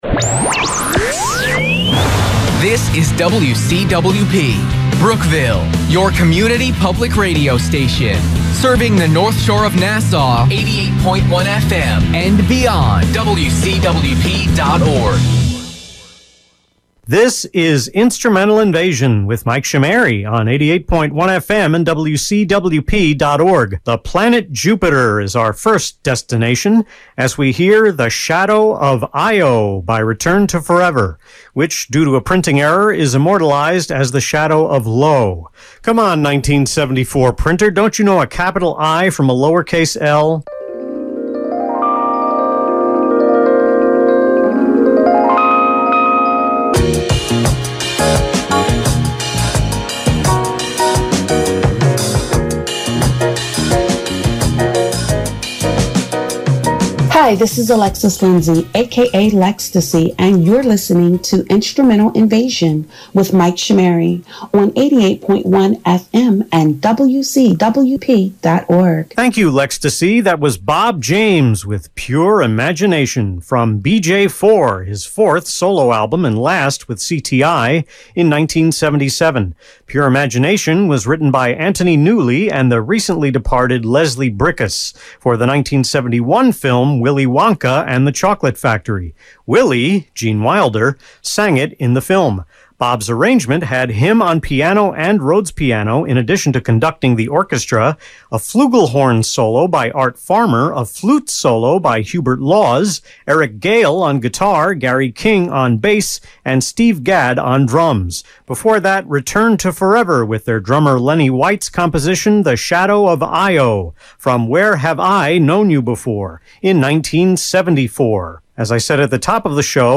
Hour 1 was recorded from my remote location (with the fluorescent lights turned off); hour 2 and pickups were recorded from home.